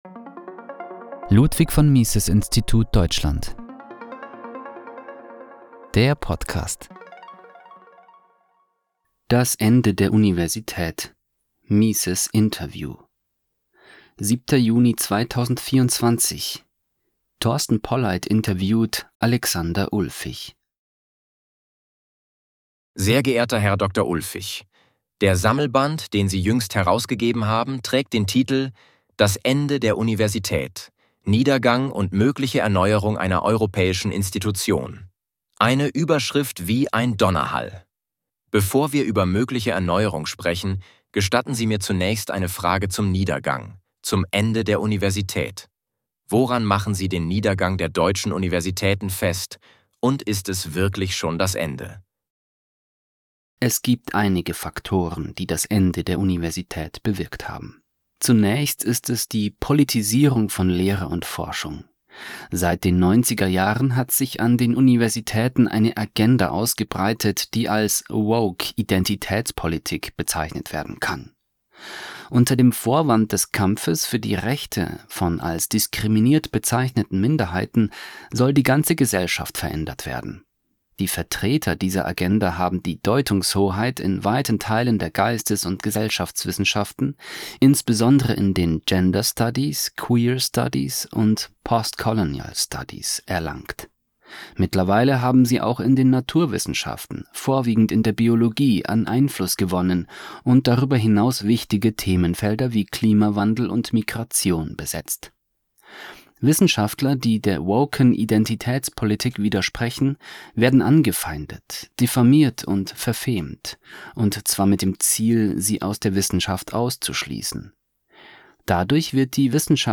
Das Ende der Universität | MISES Interview